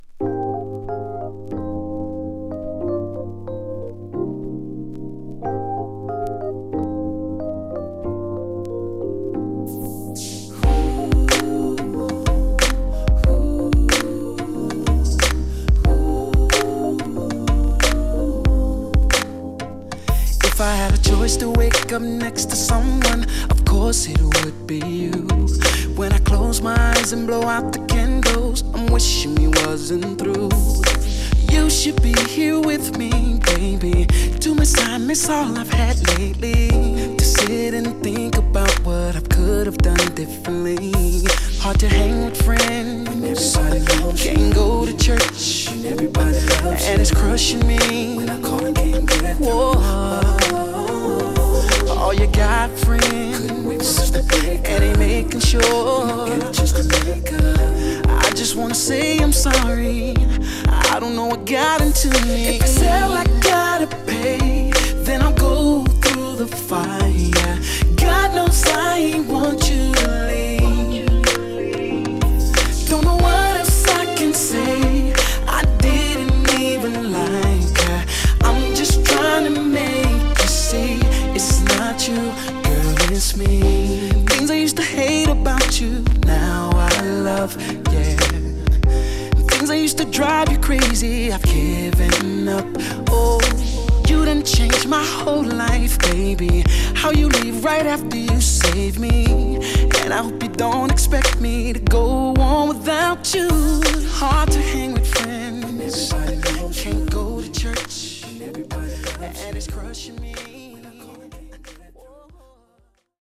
2. > R&B